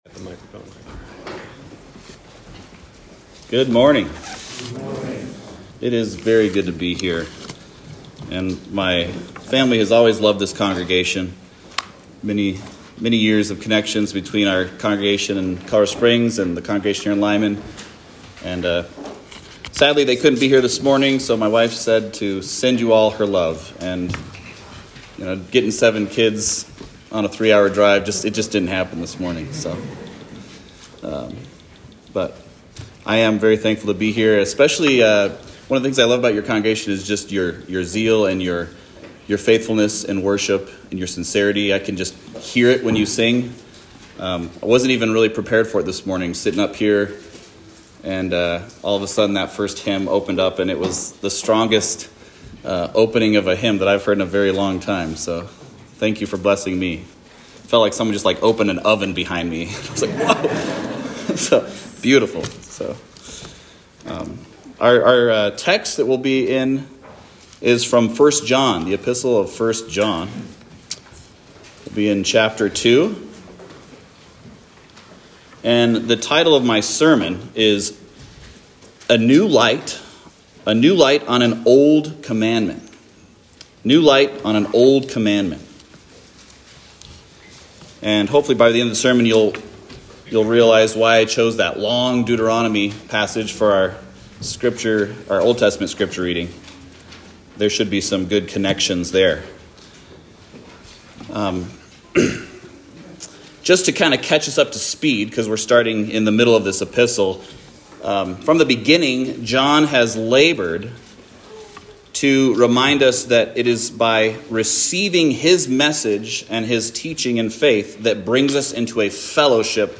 Series: Guest Preacher
Service Type: Morning Service